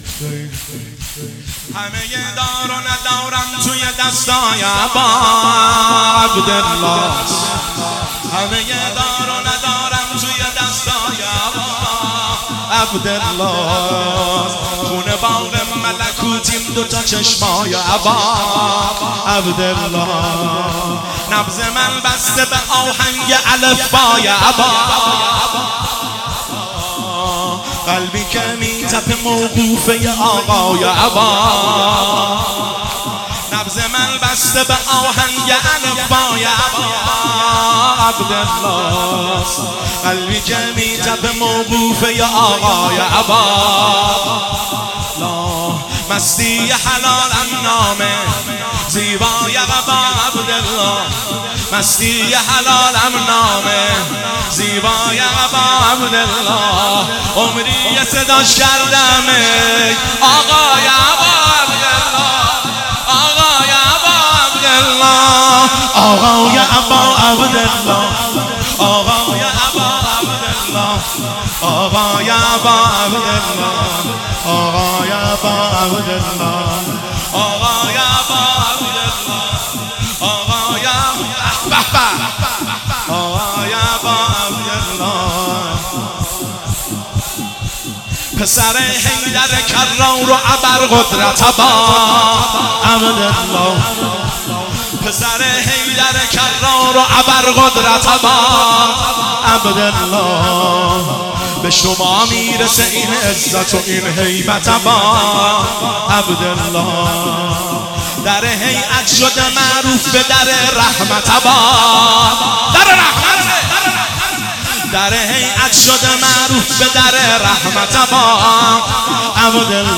هیئت بین الحرمین طهران